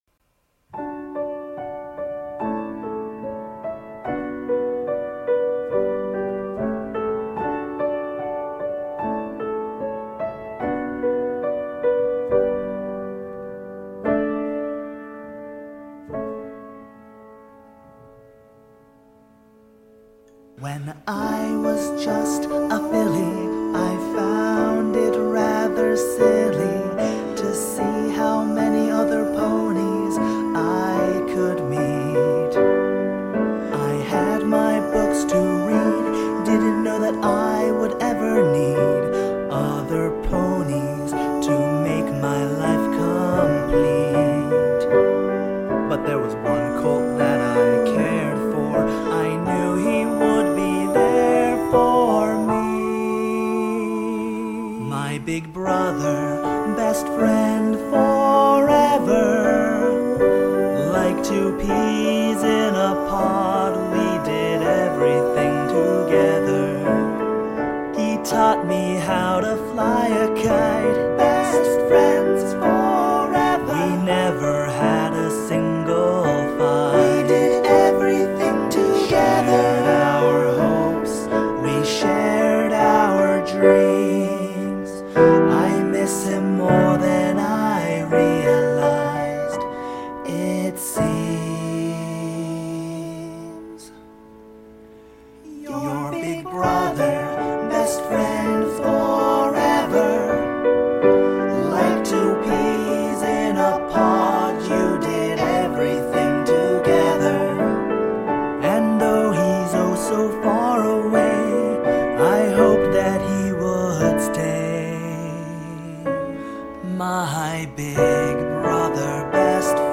piano and voice ballad